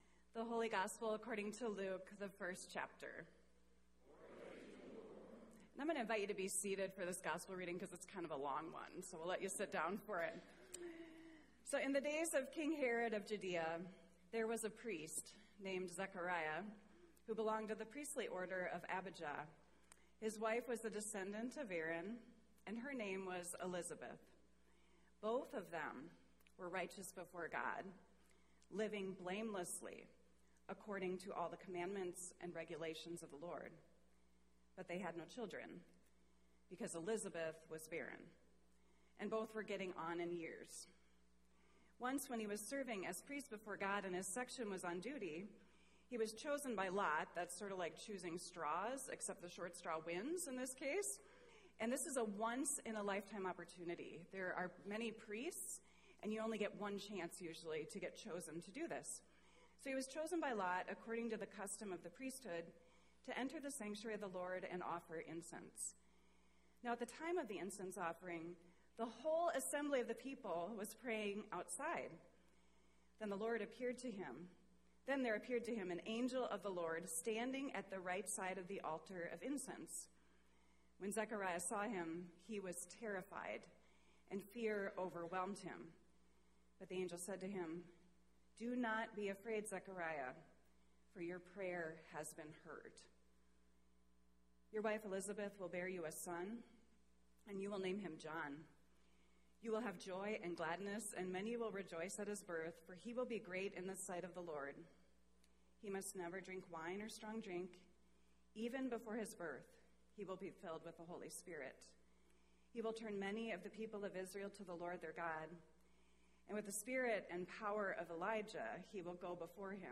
1 Ruth Series #3 24th Sunday after Pentecost